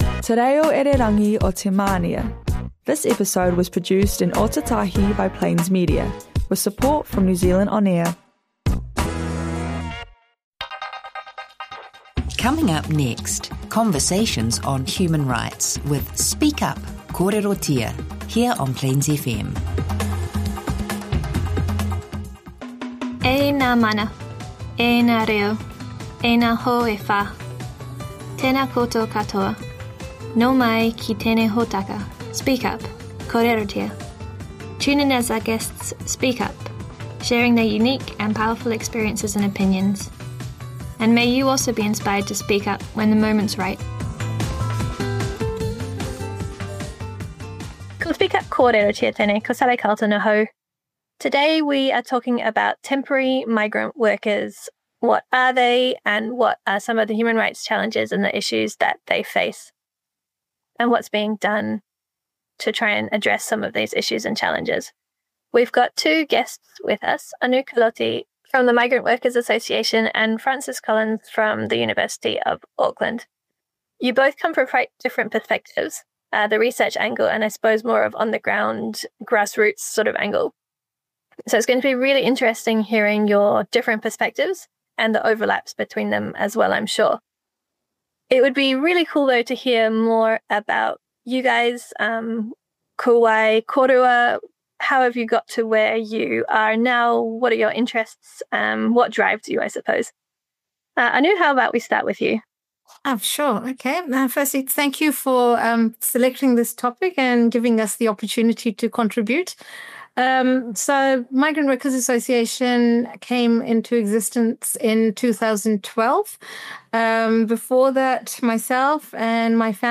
Episode of human rights radio show, Speak up - Kōrerotia, on the topic of migrant workers' rights, with suggested reading on this topic.…